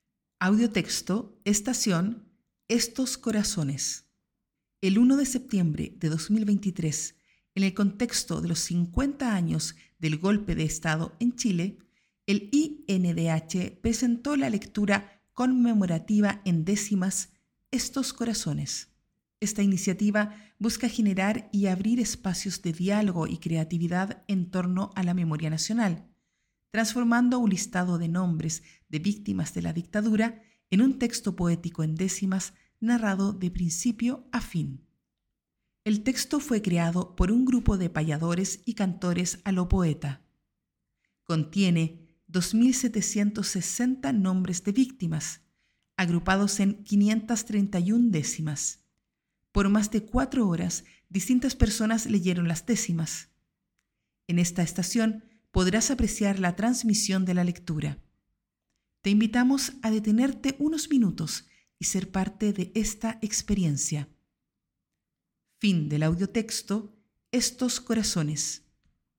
Audiotexto